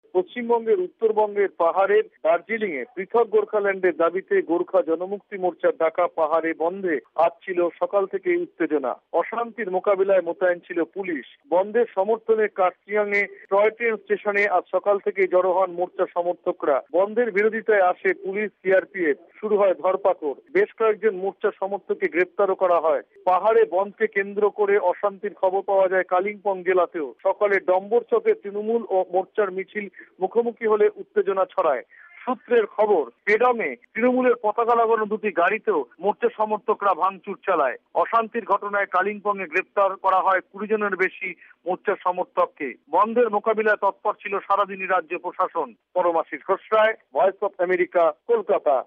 রিপোর্ট (বান্ধ)